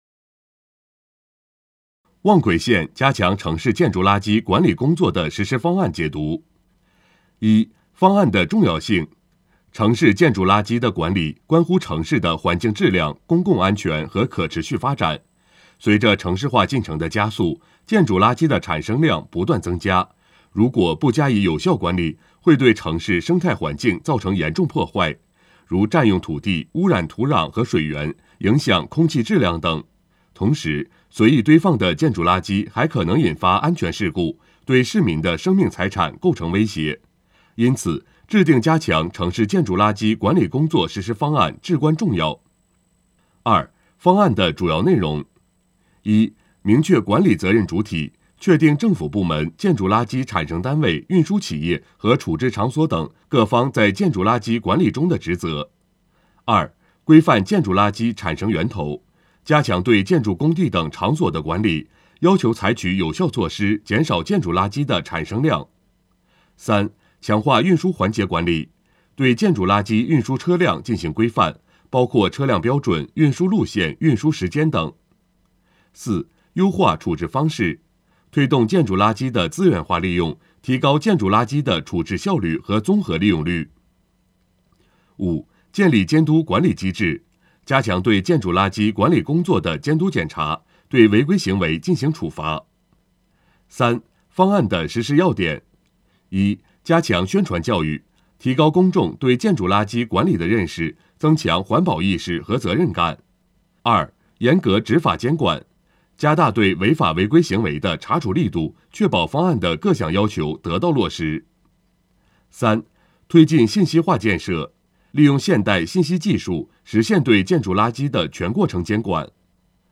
【音频解读】《关于印发望奎县加强城市建筑垃圾管理工作实施方案》的解读_望奎县人民政府